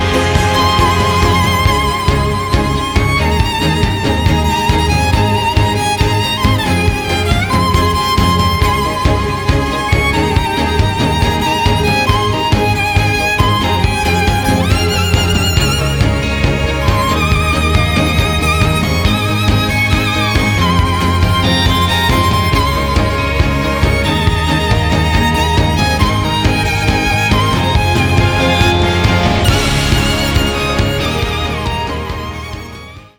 • Качество: 320, Stereo
спокойные
без слов
скрипка
инструментальные